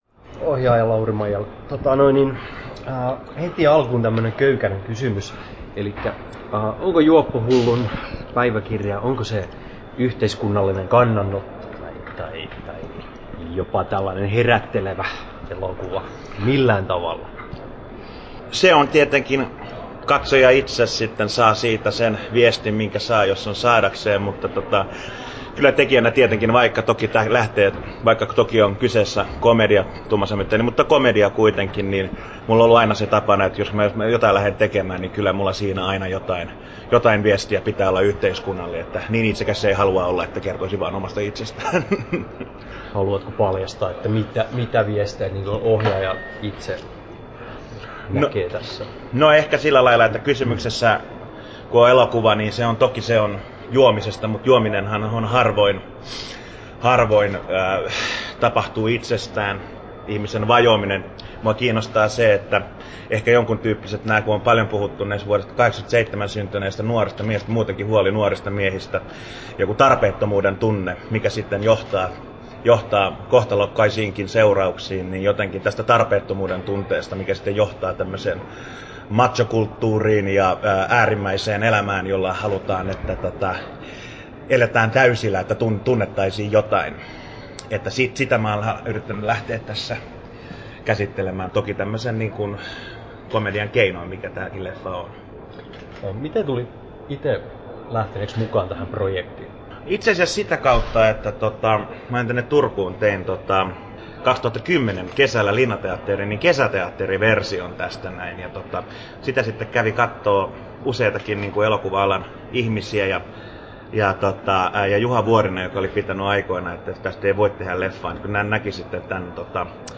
14'23" Tallennettu: 26.11.2012, Turku Toimittaja